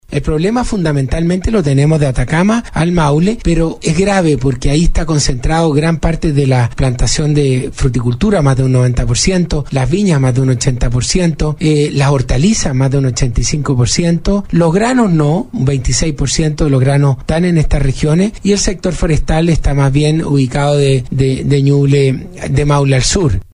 El Ministro Antonio Walker abordó el tema de la sequía en profundidad, en entrevista con Radio Agricultura, el pasado sábado.